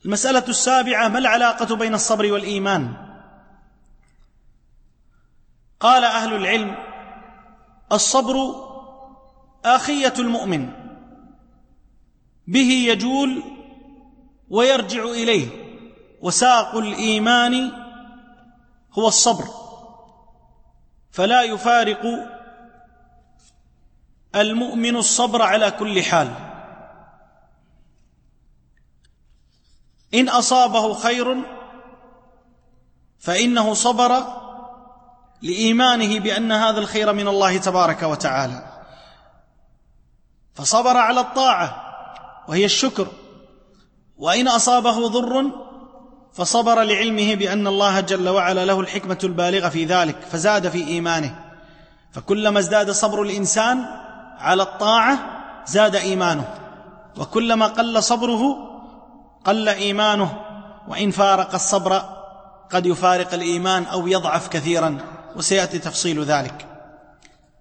التنسيق: MP3 Mono 44kHz 128Kbps (CBR)